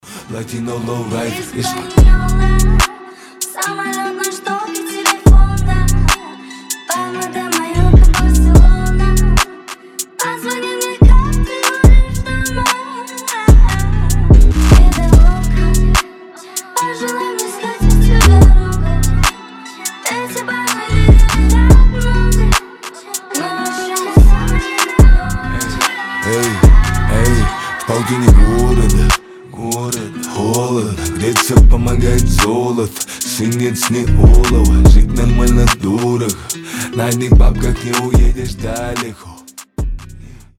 рэп , trap , лирика , дуэт
drill